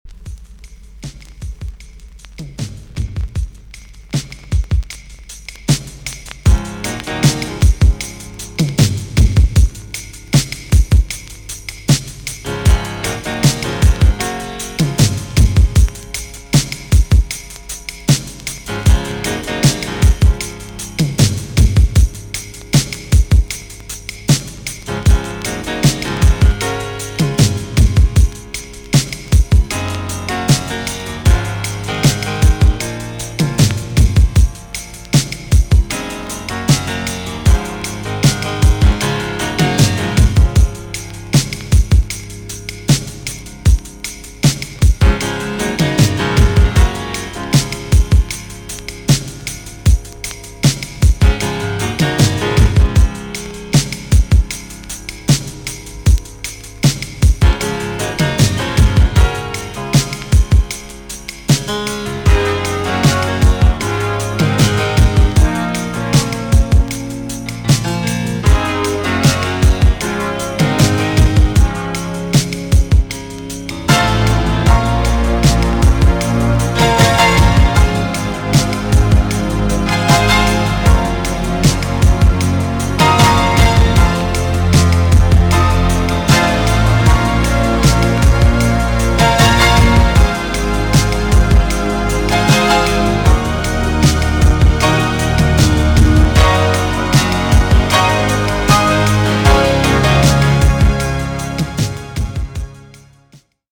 EX-~VG+ 少し軽いチリノイズが入りますが良好です。